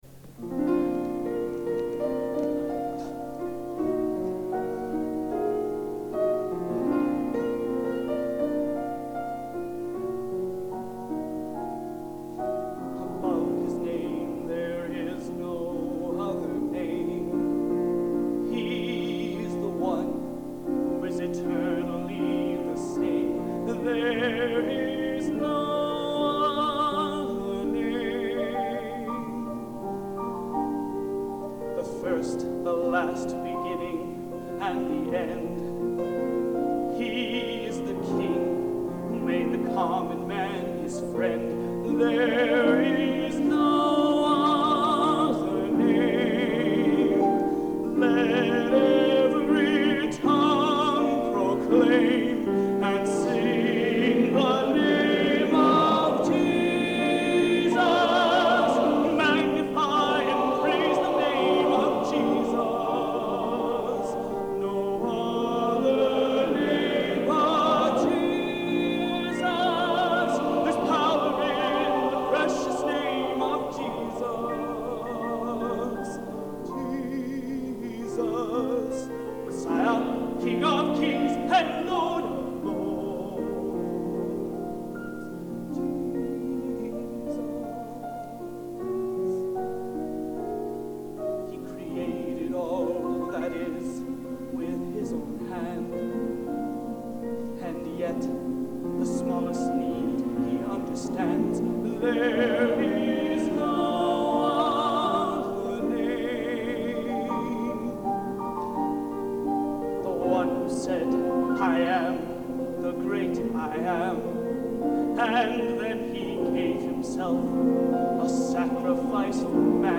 Location: Old Academy of Music, Stockholm, Sweden